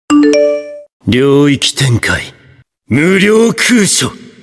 通知音 - Notification Tones